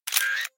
Звук снимка экрана на телефоне или компьютере